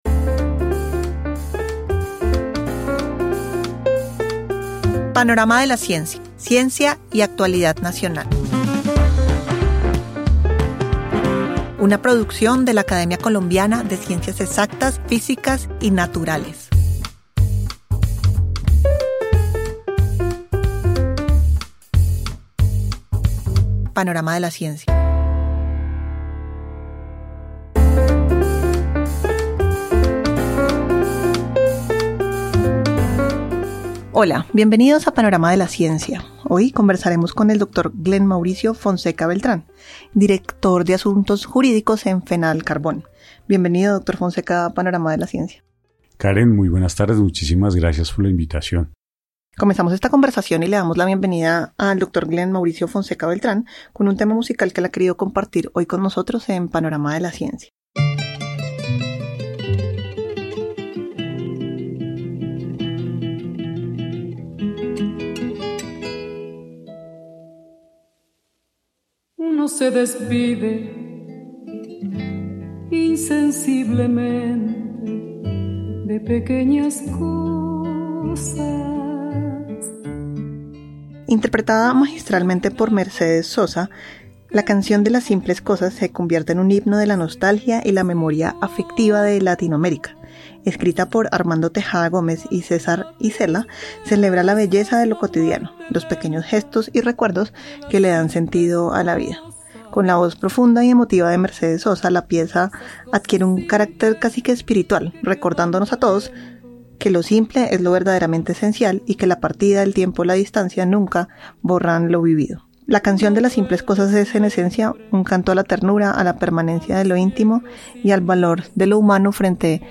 Conversación sobre los cambios de paradigmas en la producción y uso de la energía, un tema que atraviesa la transición energética, la descarbonización y la búsqueda de modelos sostenibles que respondan a los retos ambientales y económicos de nuestro tiempo.